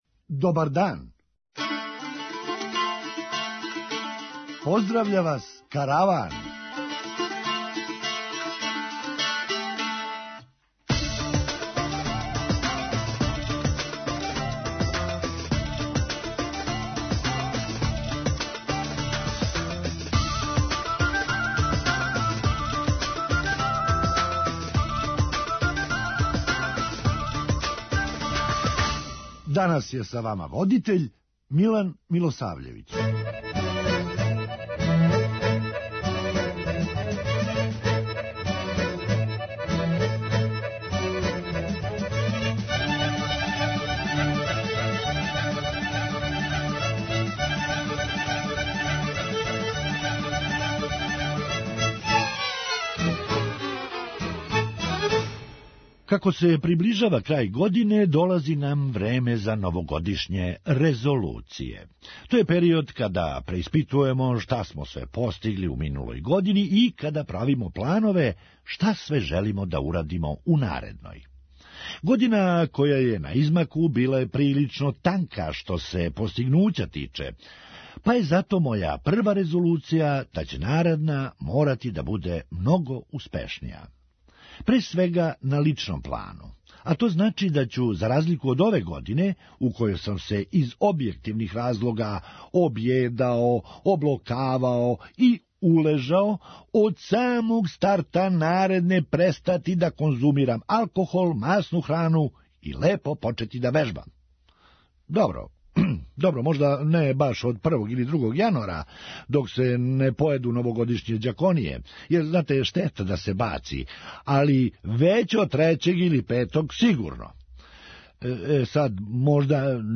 Хумористичка емисија
С тим што наш опоравак неће бити спринт, већ маратон. преузми : 9.94 MB Караван Autor: Забавна редакција Радио Бeограда 1 Караван се креће ка својој дестинацији већ више од 50 година, увек добро натоварен актуелним хумором и изворним народним песмама.